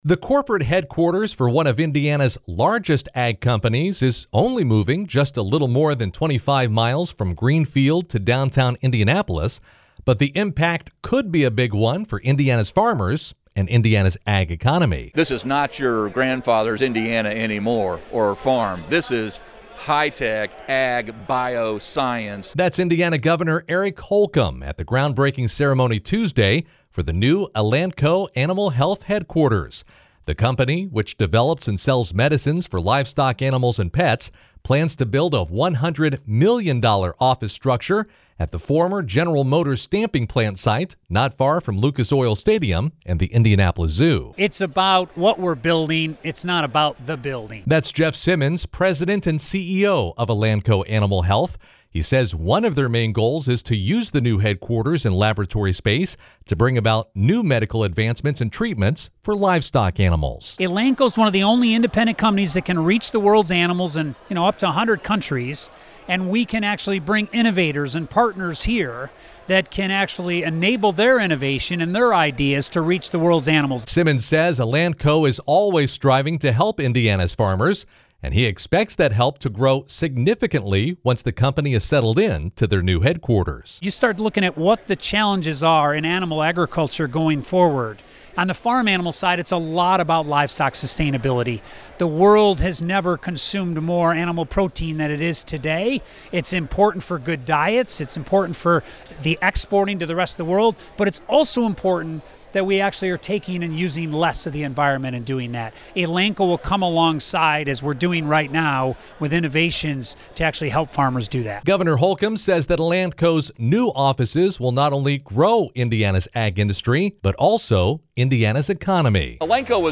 “Elanco was already on the global map, but this is going to put our state our capital city on the world map in terms of being the epicenter of animal health excellence and innovation,” says Gov. Holcomb.
cj-wrap-elanco-breaks-ground-on-new-hq-in-downtown-indy.mp3